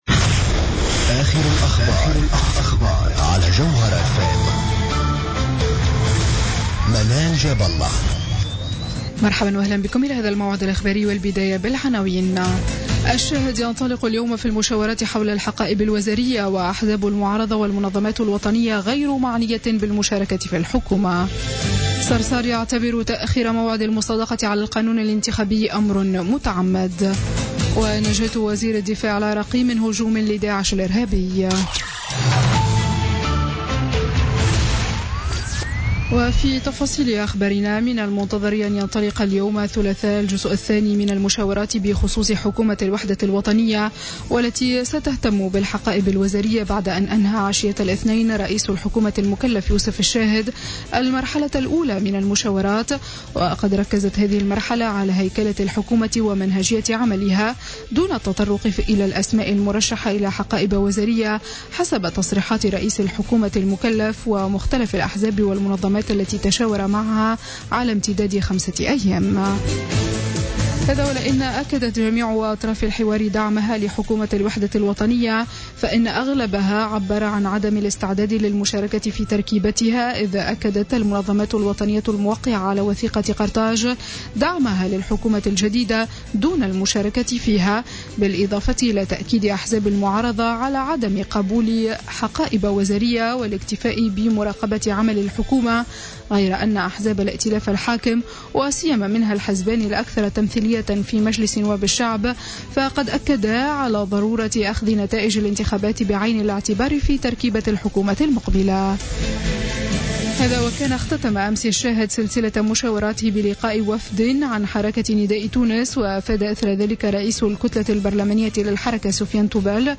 Journal info 00h00 du mardi 9 août 2016